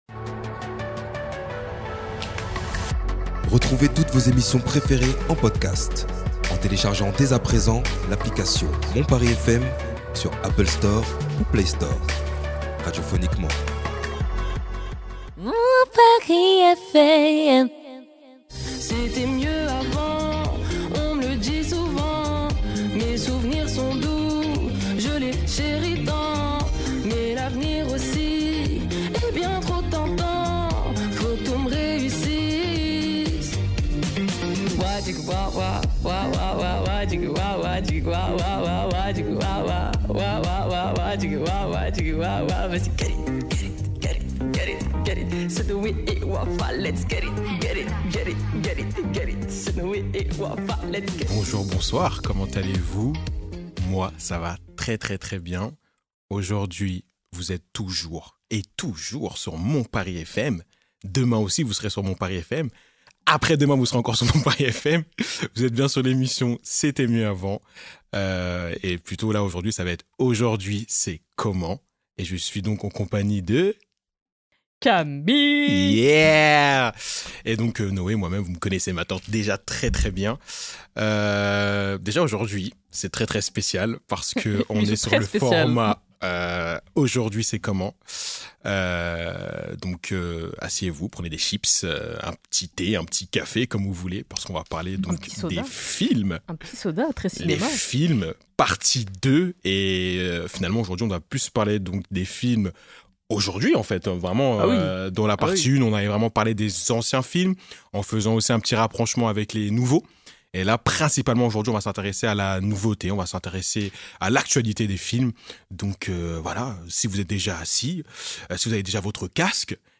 Au menu, petits jeux et débat autour du cinéma récent !Alors pour vous, les films, aujourd’hui c’est comment ?